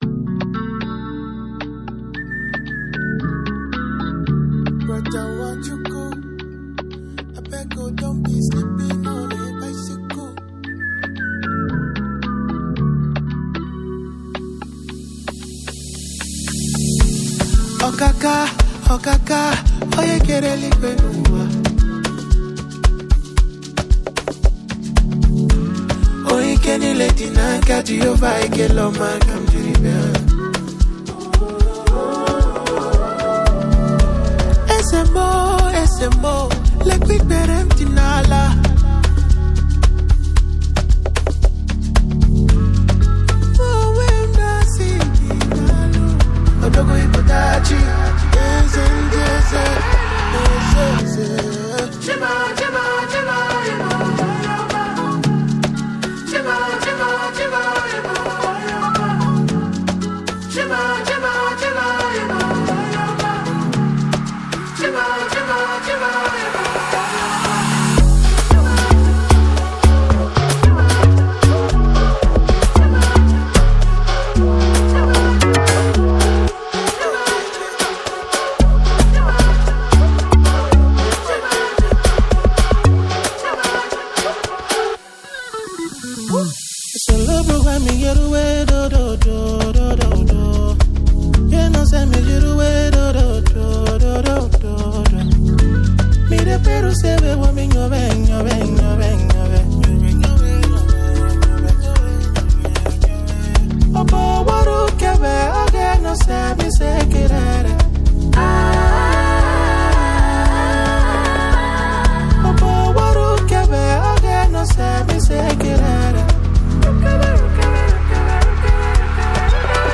Afro Beat Amapiano Music